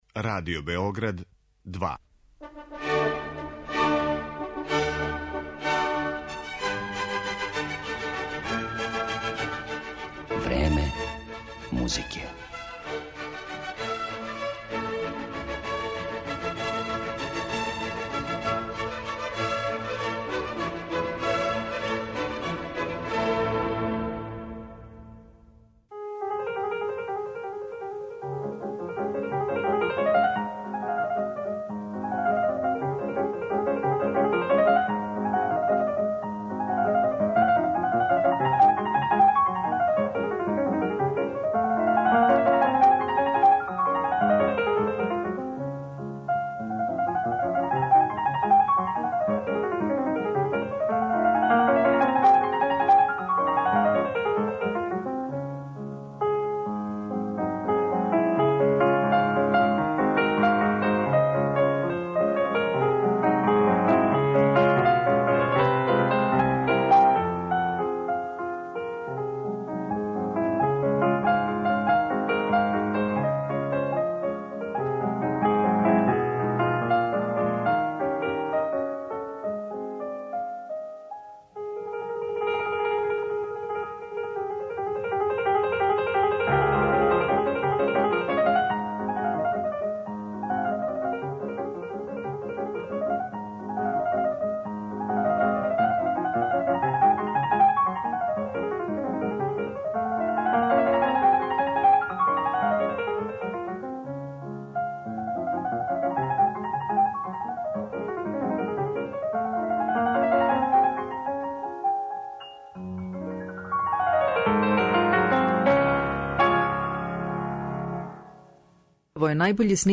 Дину Липати, музичар којем је посвећена данашња емисија, имао је каријеру која је трајала веома кратко, али је забележена као једна од најблиставијих у првој половини 20. века, Овај славни румунски пијаниста умро је са само 33 године, пре више од шест деценија, децембра 1950, али његов утицај и каризма и данас допиру до нових генерација извођача и слушалаца. Слушаћете га како изводи дела Шопена, Шуберта, Ј. С. Баха, Моцарта и Д. Скарлатија.